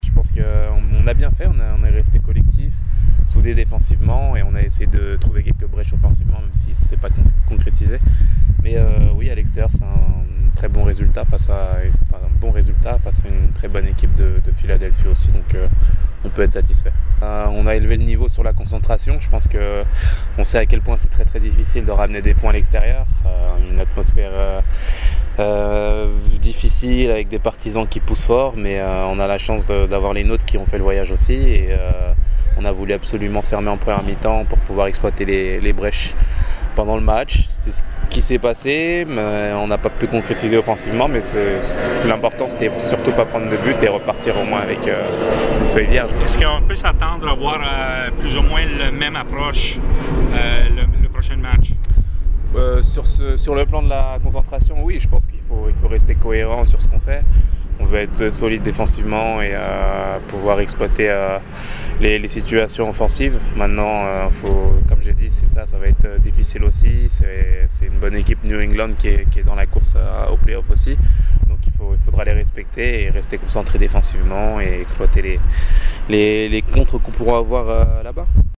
Le interviste a: